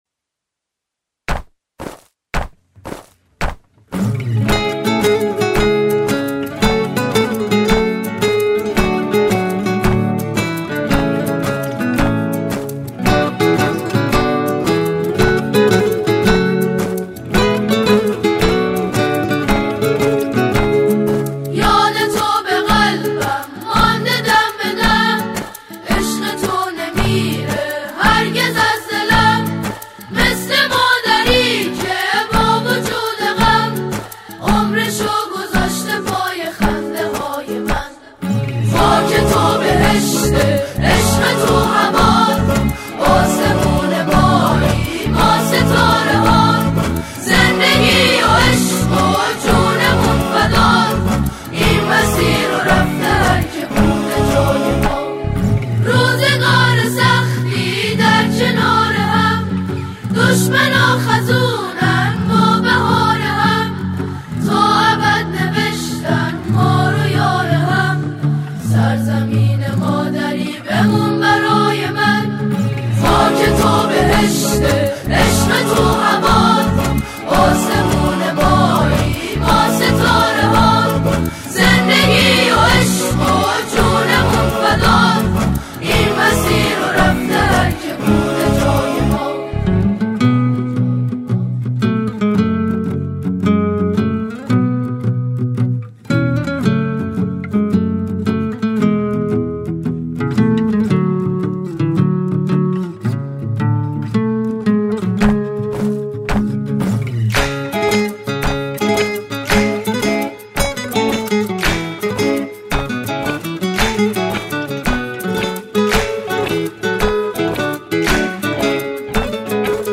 اثری میهنی و الهام‌گرفته از عشق به وطن است
ژانر: سرود